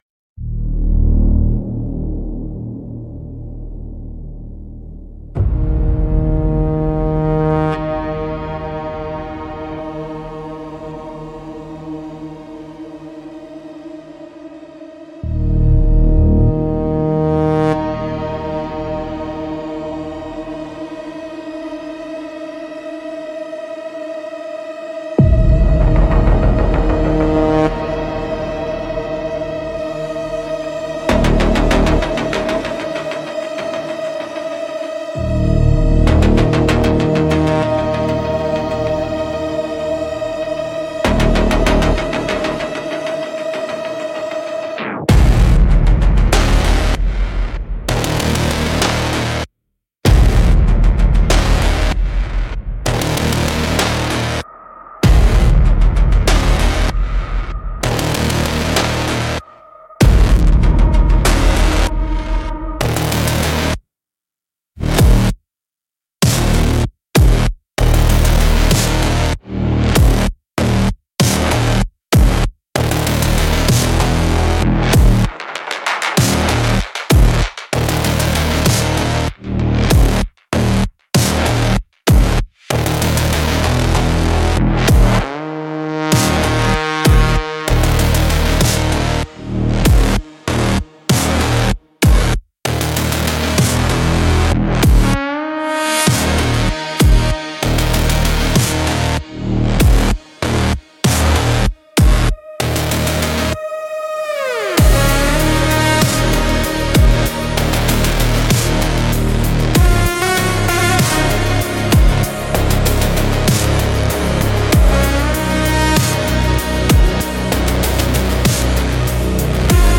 Instrumental - Unyielding Pressure 4.16